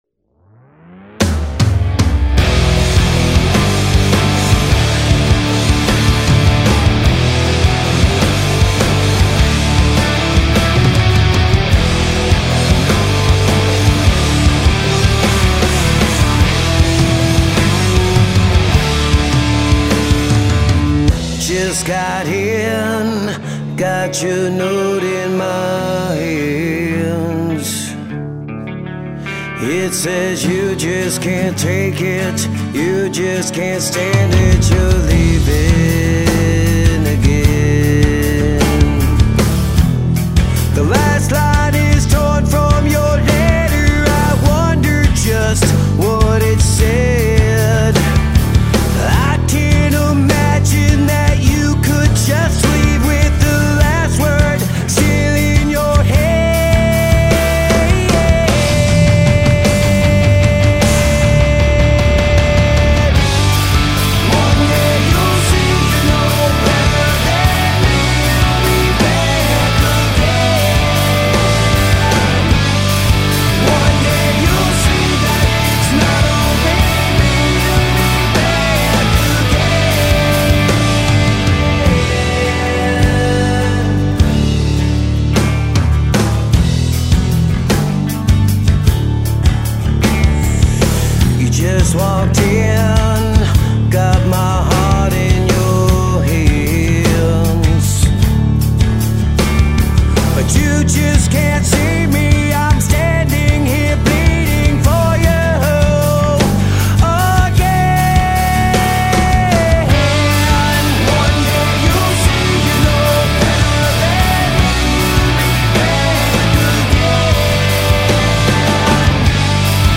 Rock & Roll
Prog rock